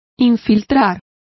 Complete with pronunciation of the translation of planting.